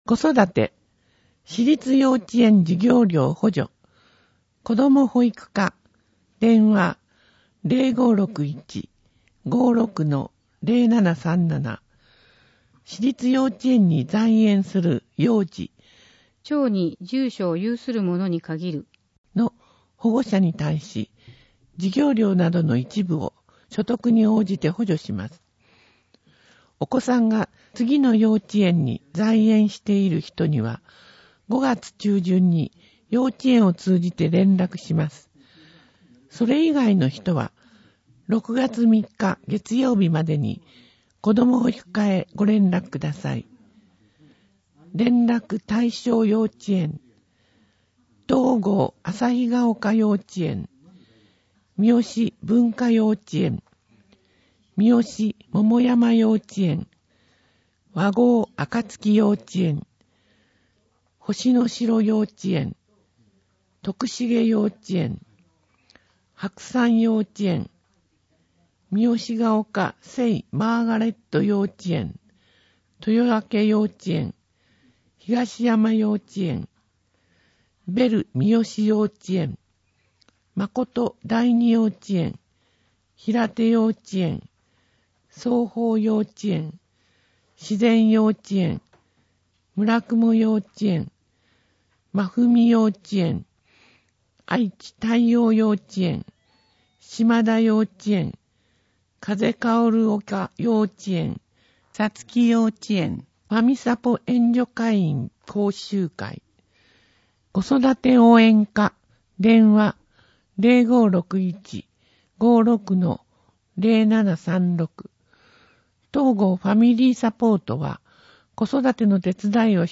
広報とうごう音訳版（2019年5月号）